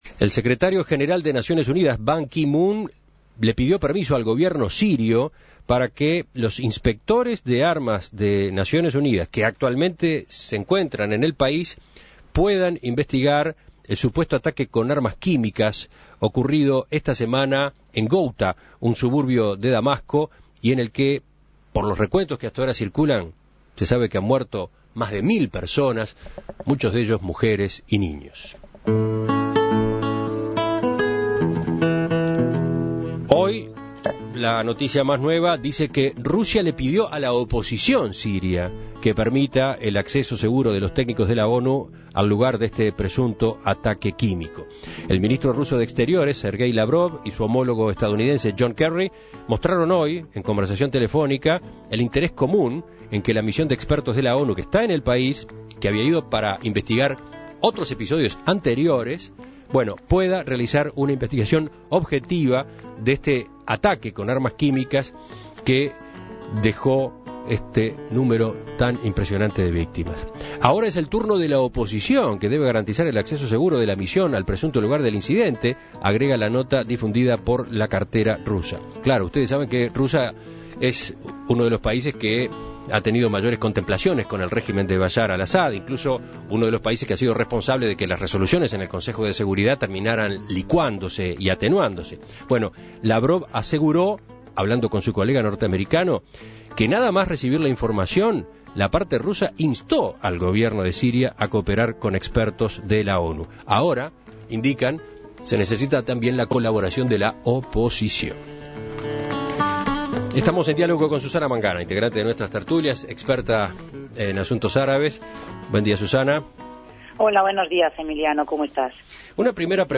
experta en asuntos árabes.